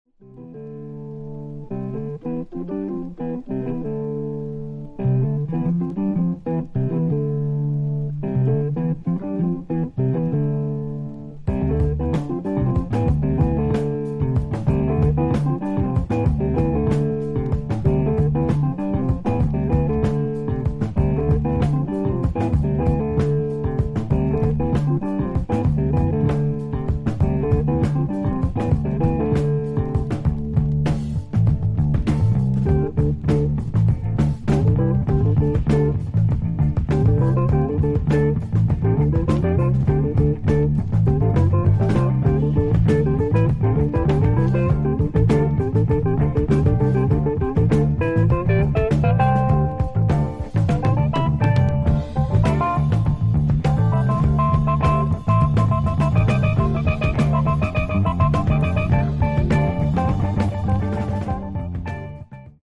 British progressive groove